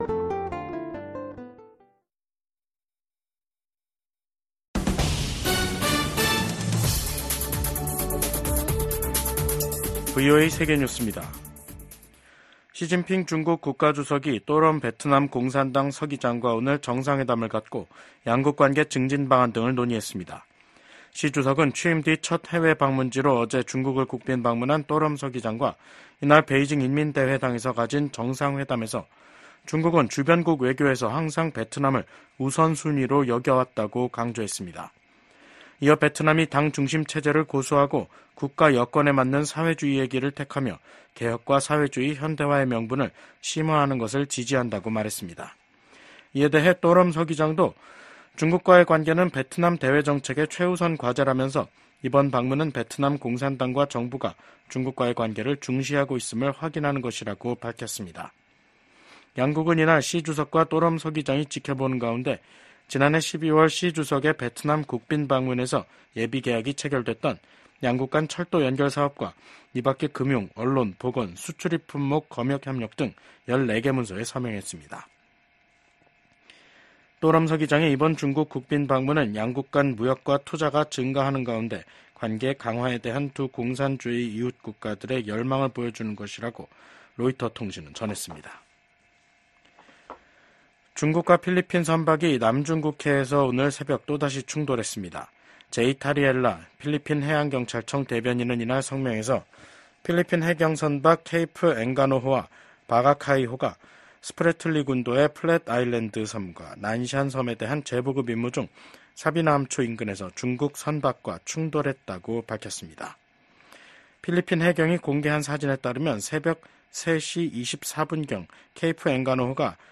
VOA 한국어 간판 뉴스 프로그램 '뉴스 투데이', 2024년 8월 19일 2부 방송입니다. 미국과 한국, 일본이 캠프 데이비드 정상회의 1주년을 맞아 공동성명을 발표했습니다. 북한이 올해 말부터 제한적으로 외국인 관광을 재개할 것으로 알려진 가운데 미국은 자국민 방북을 절대 불허한다는 방침을 거듭 확인했습니다. 미국 정부는 윤석열 한국 대통령이 발표한 ‘8.15 통일 독트린’에 대한 지지 입장을 밝혔습니다.